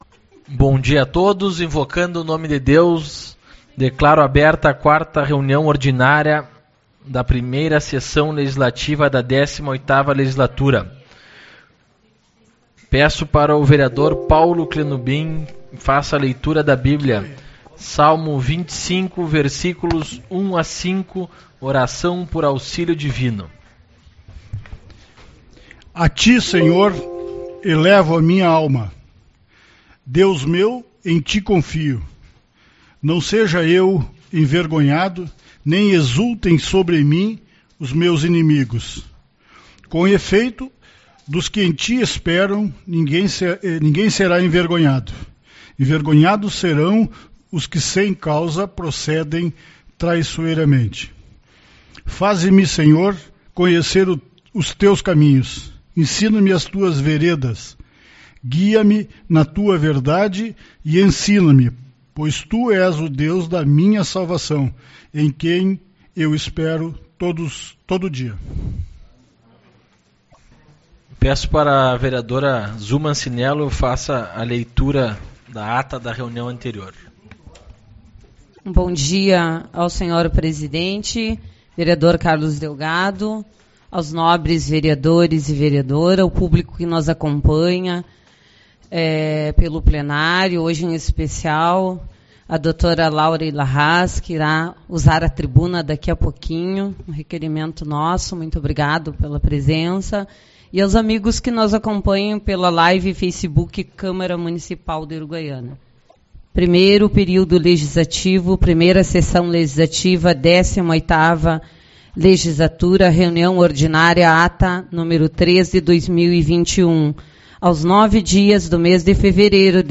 11/02 - Reunião Ordinária